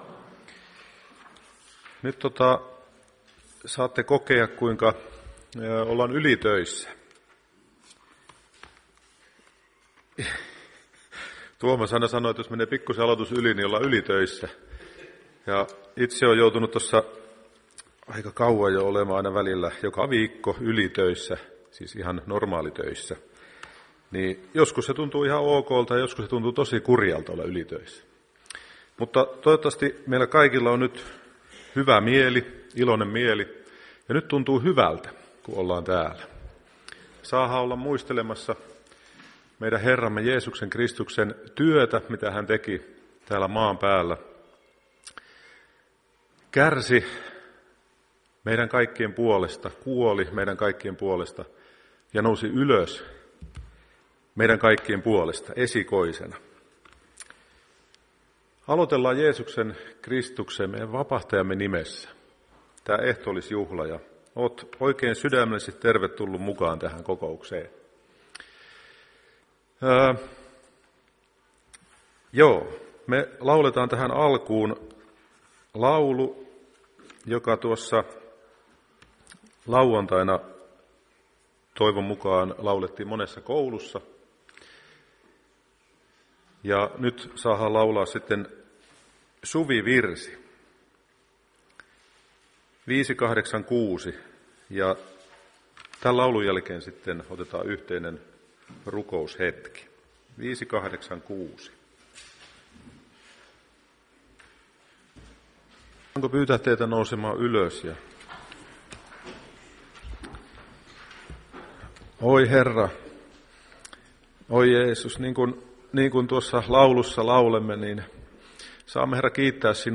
Ehtoolliskokous 5.6.2022